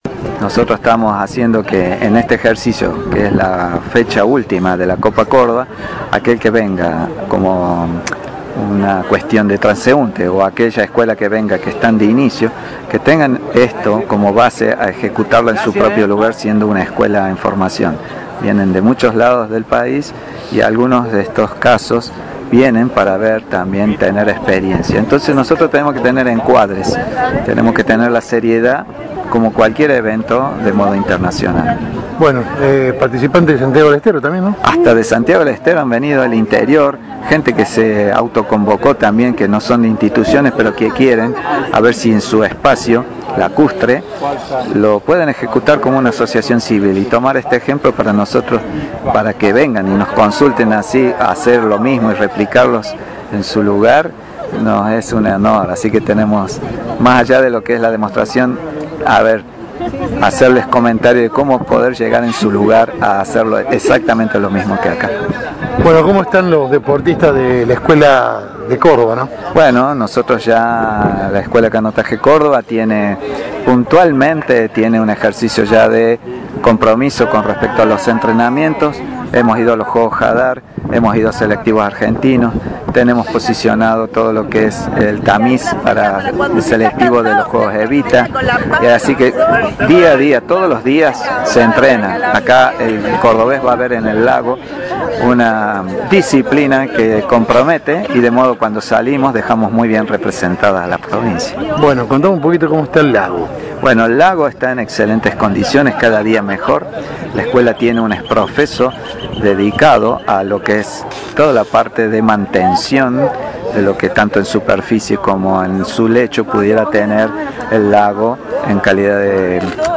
Audio nota